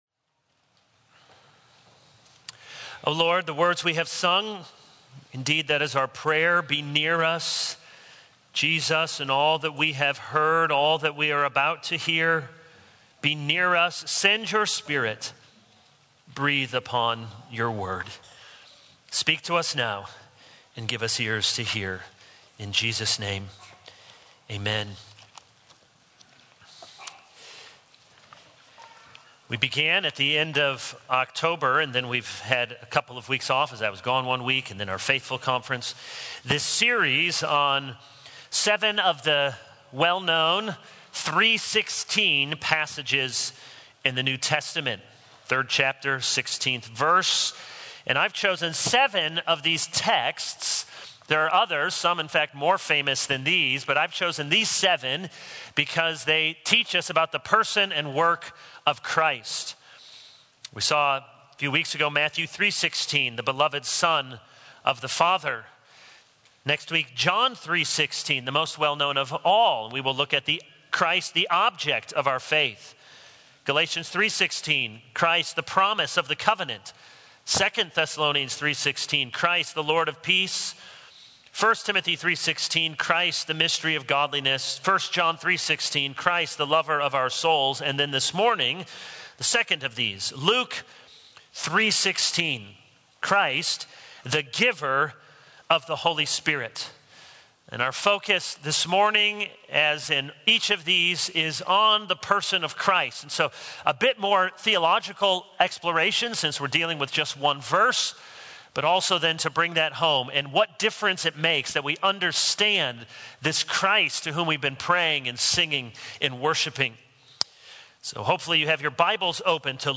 November 20, 2022 | Sunday Morning